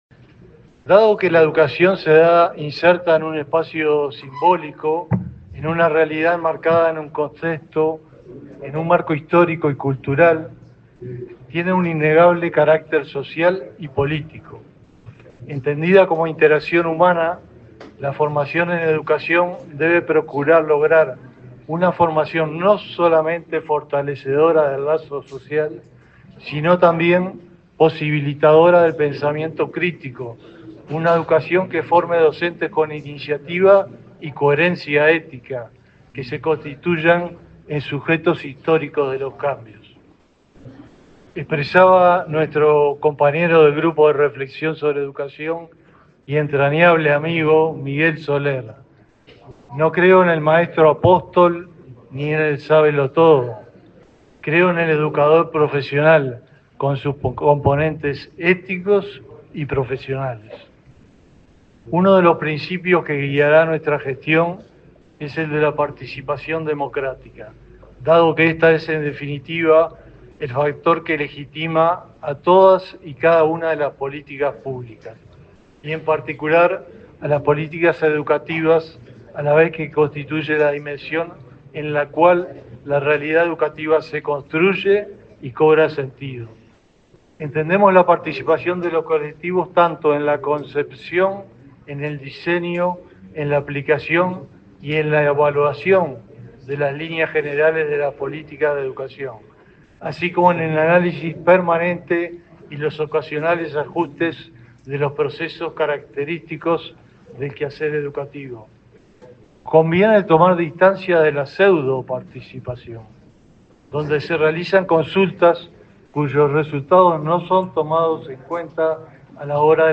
Palabras de autoridades en acto de ANEP
Las autoridades del Consejo de Formación en Educación asumieron este jueves 3 en Montevideo.
Durante la ceremonia, se expresaron el presidente de la institución, Walter Fernández; su par de la Administración Nacional de Educación Pública (ANEP), Pablo Caggiani, y la subsecretaria de Educación y Cultura, Gabriela Verde.